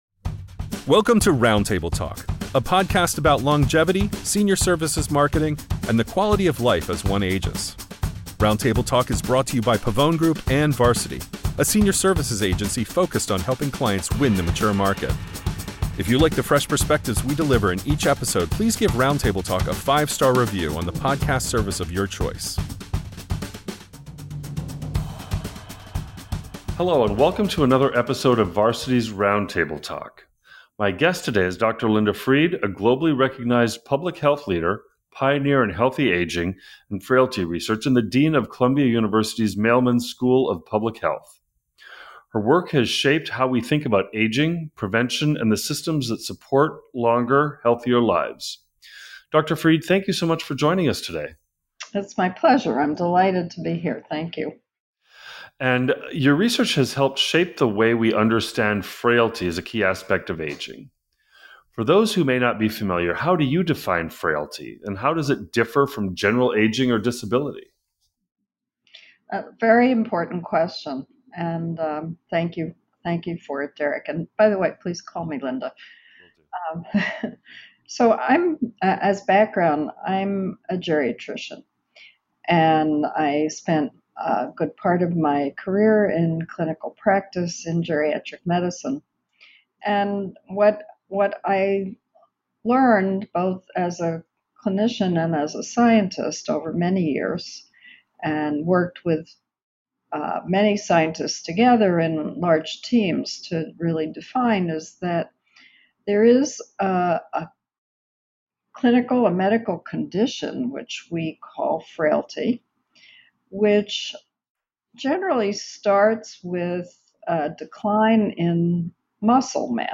On this episode of Varsity’s Roundtable Talk, we sit down with Dr. Linda Fried, a world-renowned geriatrician, public health expert, and Dean of Columbia University’s Mailman School of Public Health.